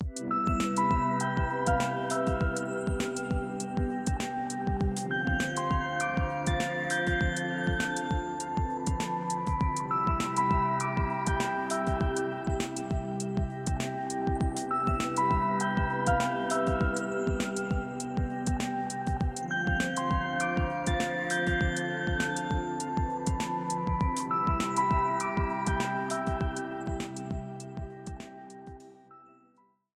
Self-ripped
Combined audio stems, shortened to 30 seconds, added fadeout